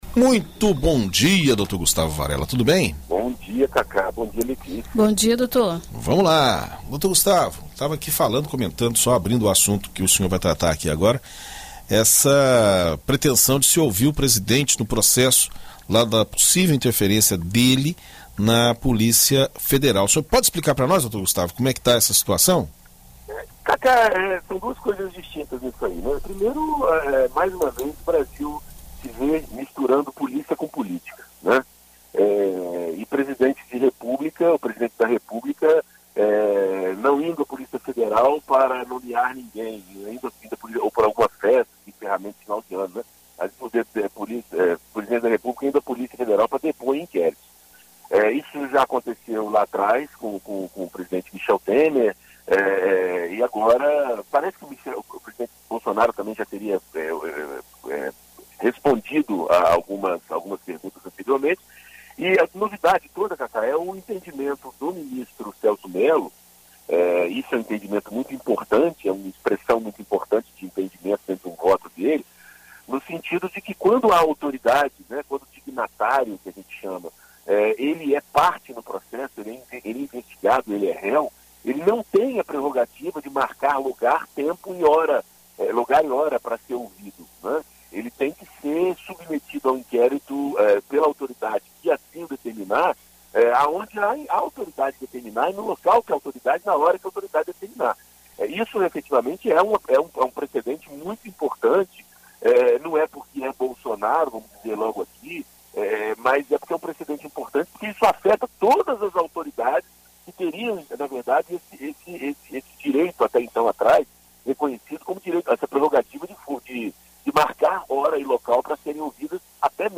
Confira o comentário na BandNews FM Espírito Santo: